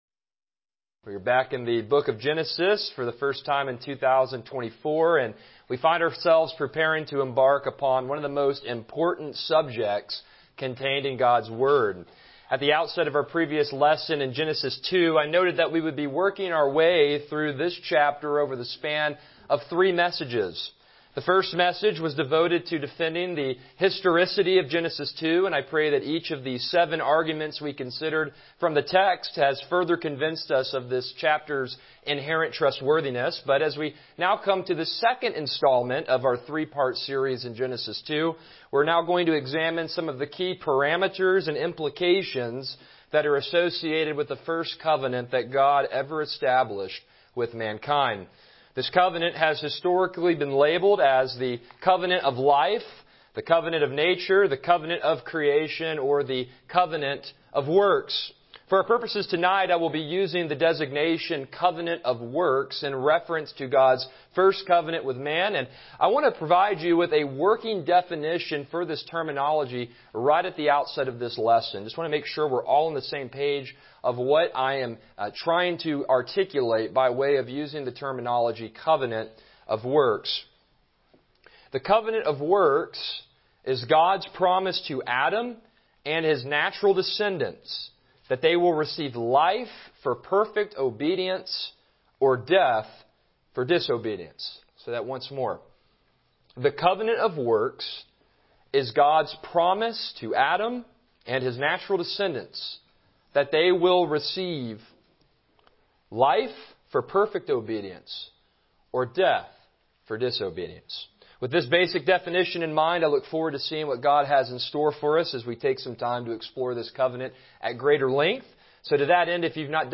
Passage: Genesis 2:15-17 Service Type: Evening Worship